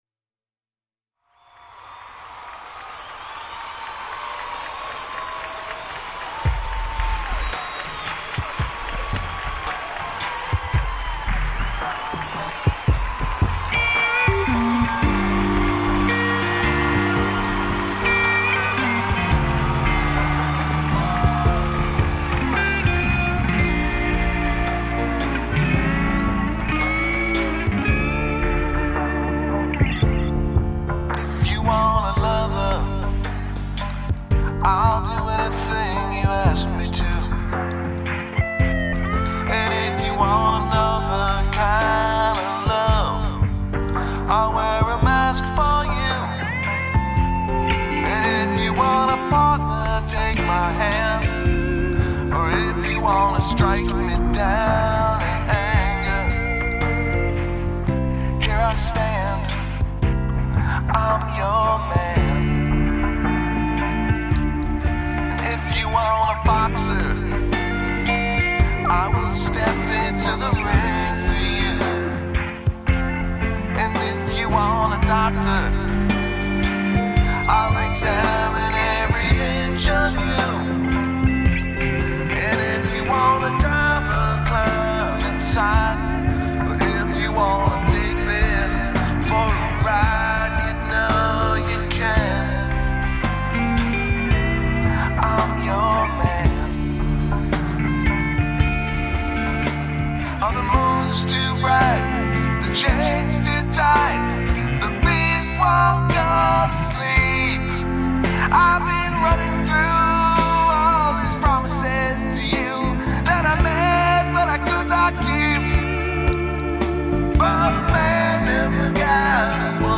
Instrument:Guitar and Vocals
Plus it's fun to play guitar over.